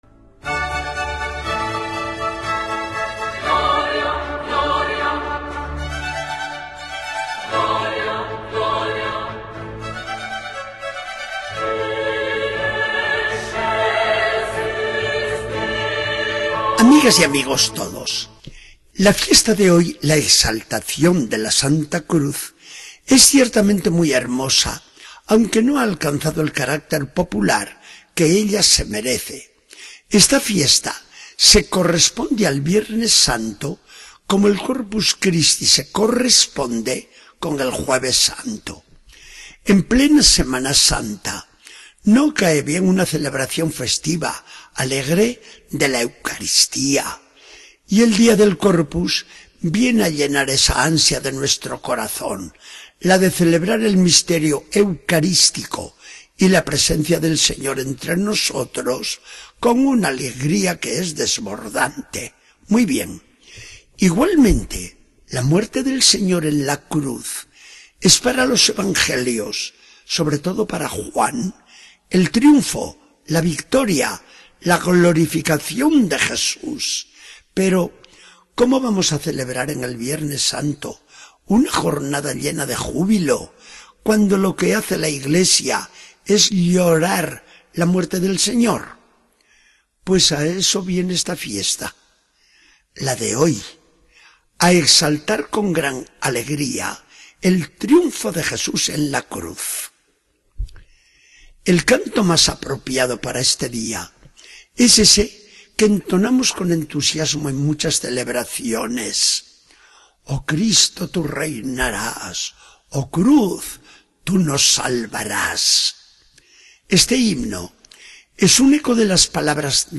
Charla del día 14 de septiembre de 2014. Del Evangelio según San Juan 3, 13-17.